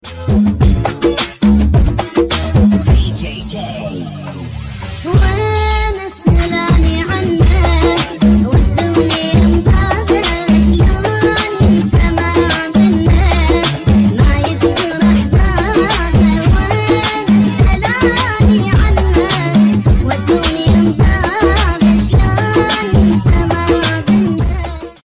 Karachi railway station 🚉🎶 • sound effects free download